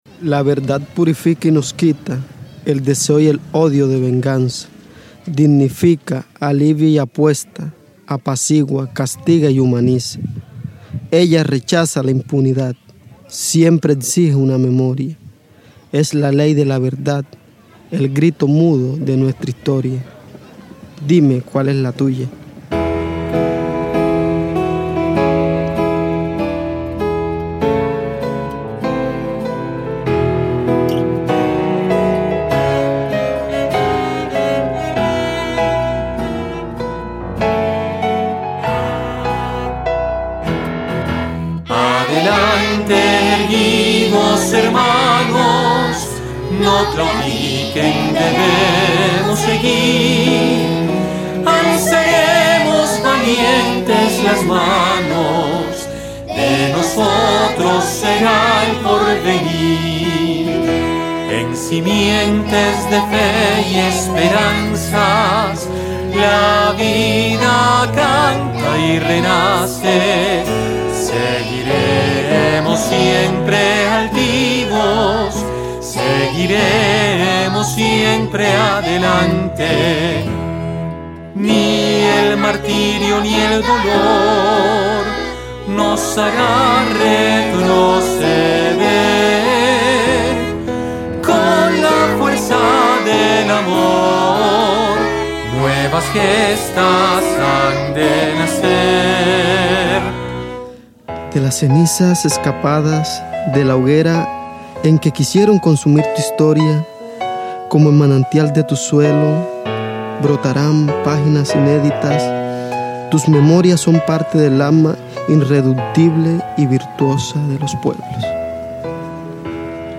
cantante
chelista